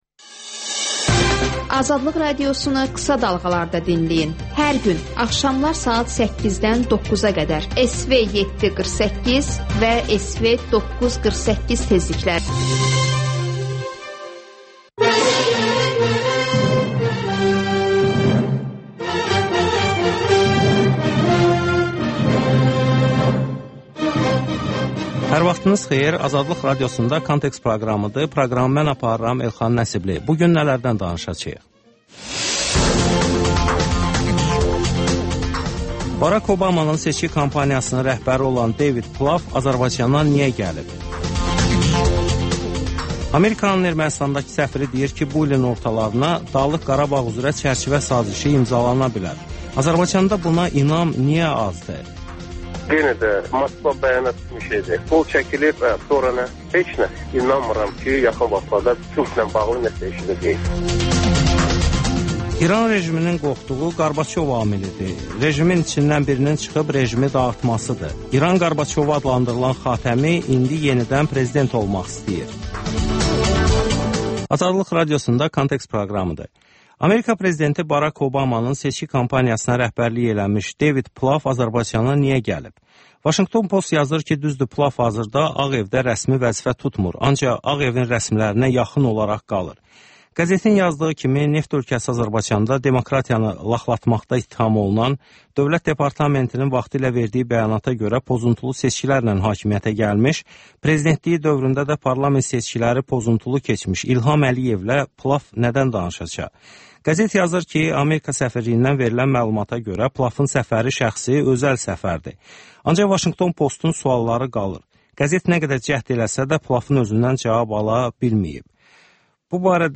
Müsahibələr, hadisələrin müzakirəsi, təhlillər Təkrar